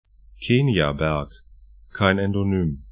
Aussprache
Keniaberg 'ke:nĭabɛrk Mlima ya Kenya 'mli:ma ja 'ke:nja kisu.